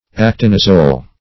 actinozoal - definition of actinozoal - synonyms, pronunciation, spelling from Free Dictionary Search Result for " actinozoal" : The Collaborative International Dictionary of English v.0.48: Actinozoal \Ac`ti*no*zo"al\, a. (Zool.) Of or pertaining to the Actinozoa.